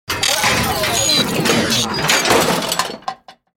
جلوه های صوتی
دانلود صدای ربات 42 از ساعد نیوز با لینک مستقیم و کیفیت بالا